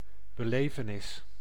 Ääntäminen
Synonyymit beleving ervaring wederwaardigheid lotgeval Ääntäminen Haettu sana löytyi näillä lähdekielillä: hollanti Käännös Ääninäyte Substantiivit 1. événement {m} France 2. expérience {f} France Suku: f .